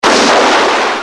دانلود صدای بمب و موشک 31 از ساعد نیوز با لینک مستقیم و کیفیت بالا
جلوه های صوتی